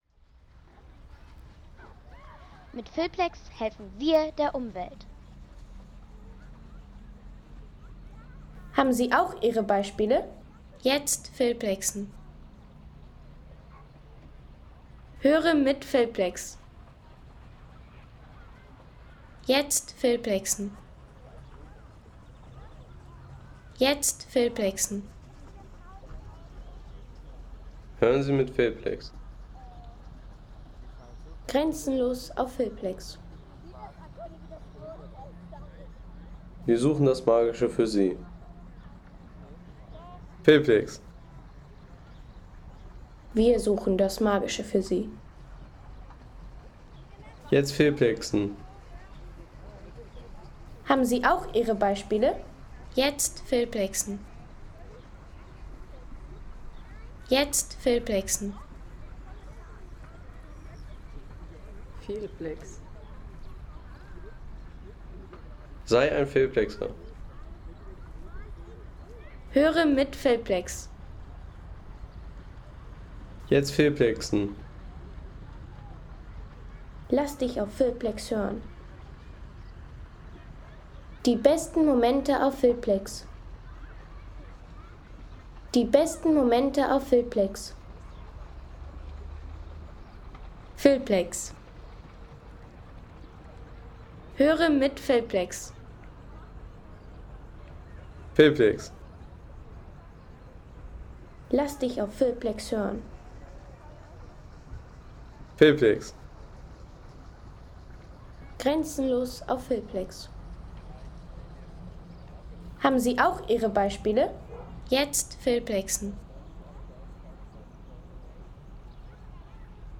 Seefelder Wildsee Home Sounds Landschaft Bäche/Seen Seefelder Wildsee Seien Sie der Erste, der dieses Produkt bewertet Artikelnummer: 226 Kategorien: Landschaft - Bäche/Seen Seefelder Wildsee Lade Sound....